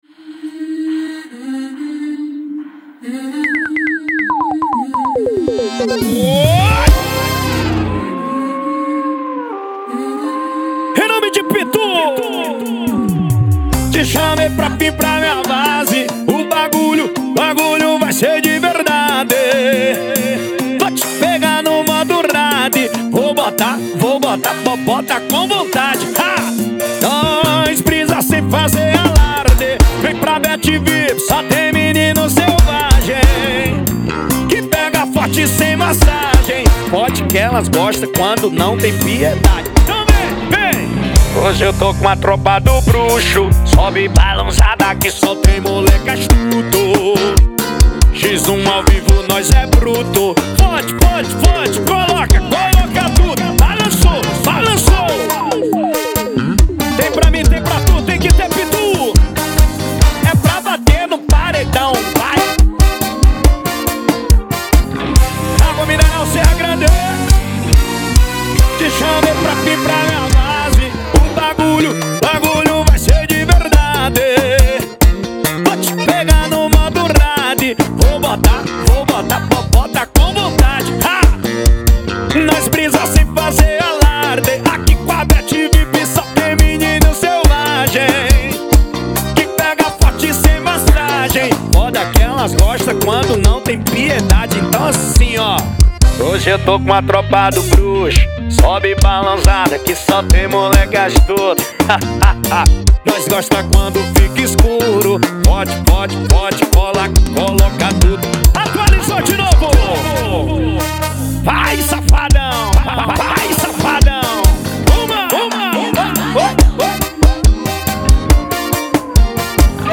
2024-02-14 22:37:07 Gênero: Forró Views